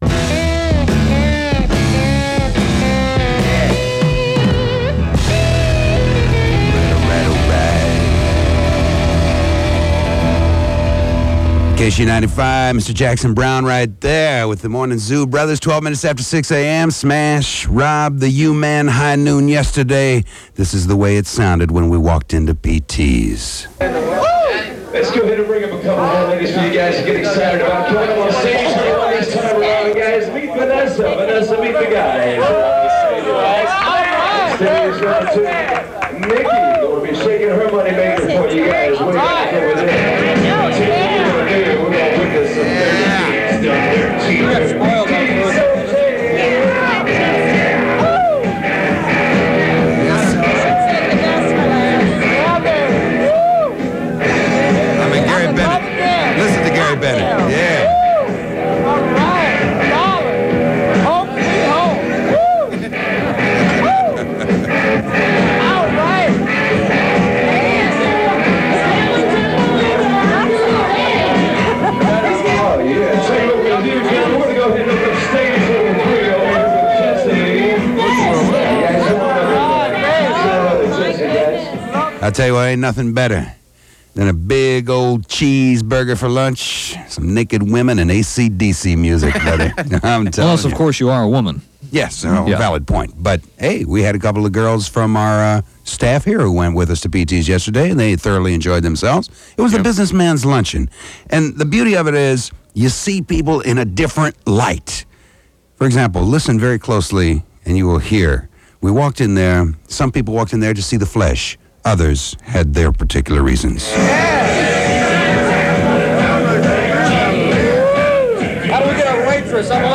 KSHE Smash Aircheck · St. Louis Media History Archive